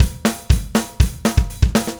Power Pop Punk Drums 01a.wav